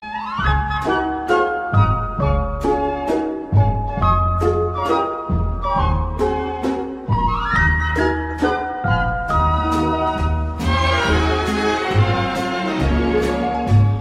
Instrumental Version Of A Holiday Classic!